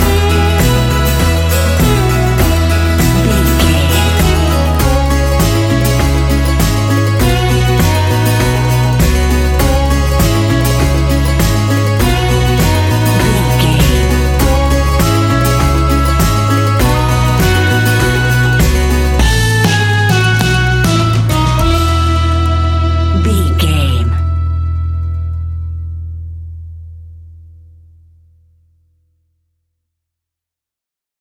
Uplifting
Ionian/Major
acoustic guitar
mandolin
ukulele
lapsteel
drums
double bass
accordion